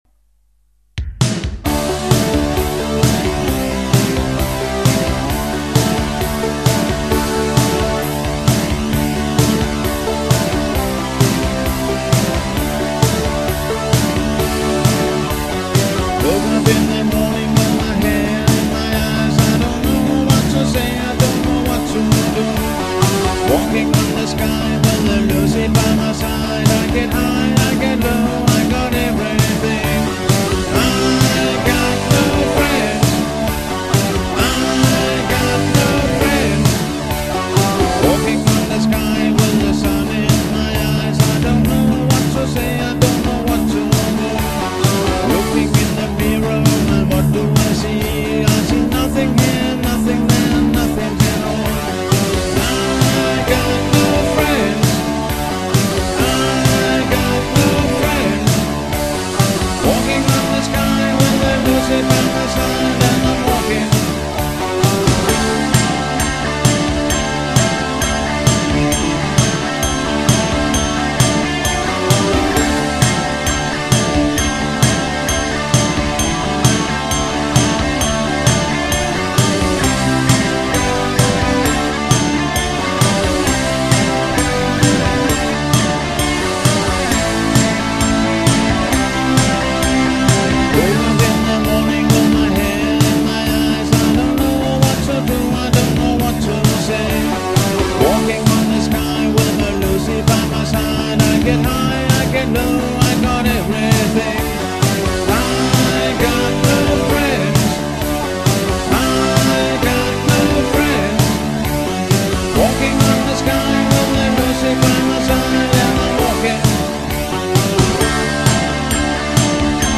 Sang, guitar og synth
Bas og trommer: PC